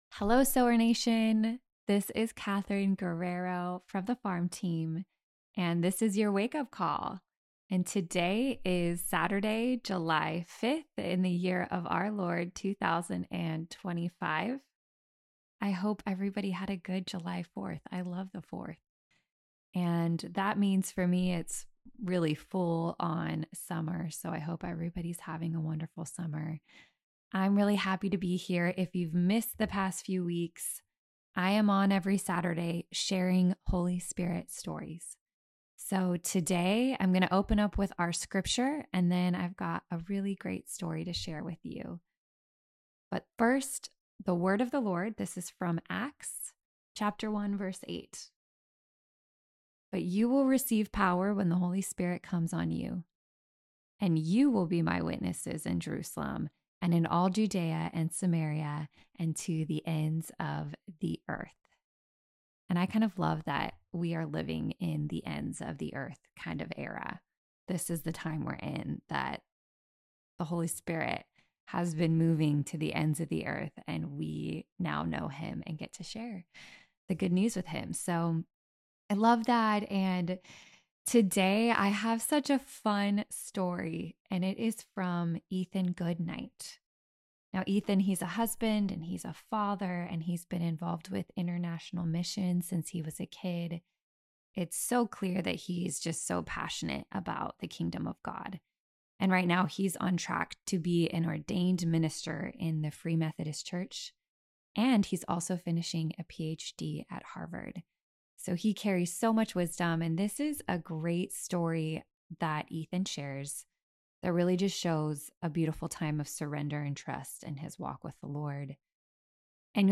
In it you'll see the fruit of laying down your plans for God's greater plans. Below, you will read the story in his words, and, if you listen, you will hear it in his own voice.